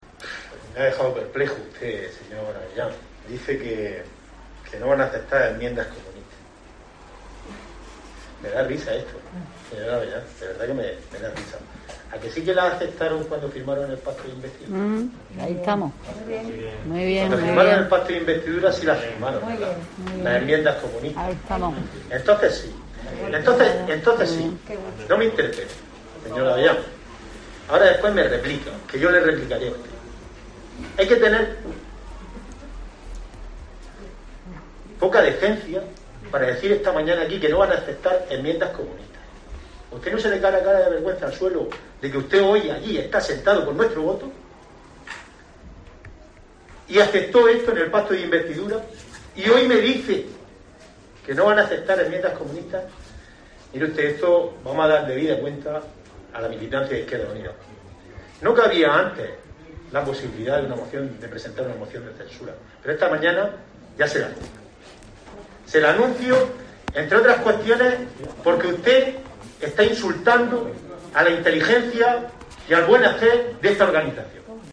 Pedro Sosa, portavoz de IU Verdes en el pleno municipal